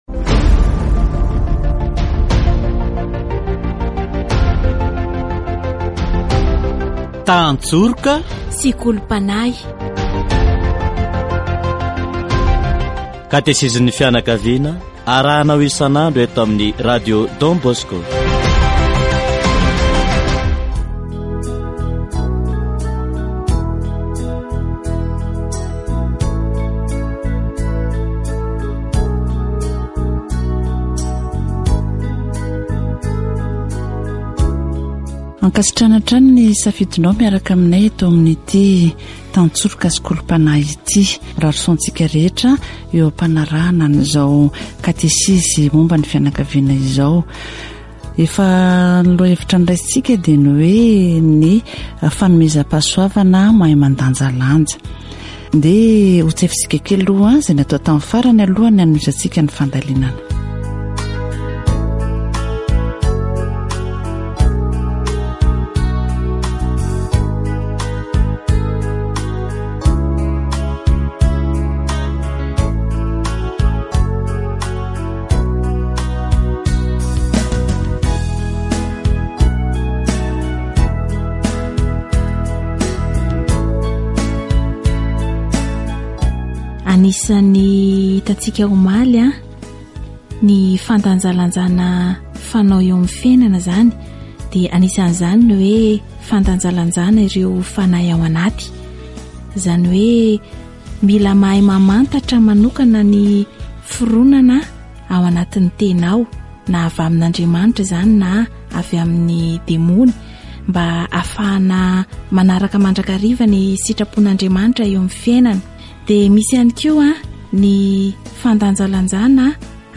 Katesizy momba ny fanomezam-pahasoavana fahaizana mandanjalanja